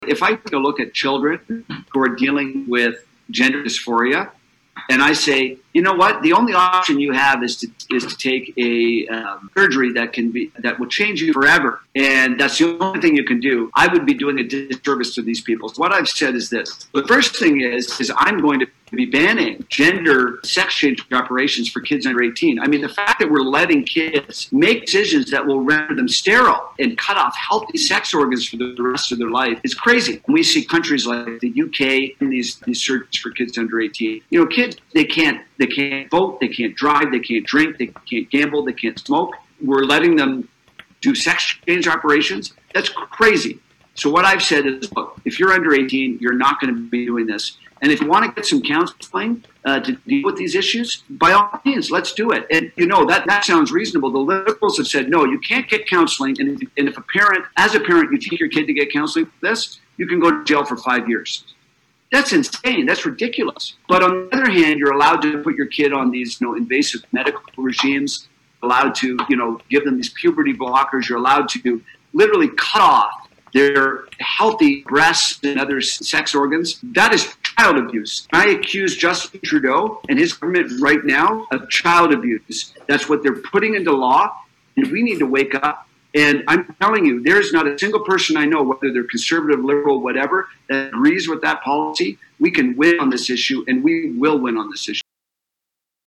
Here was Sloan during a webinar Tuesday night.
Here is his full answer during the webinar.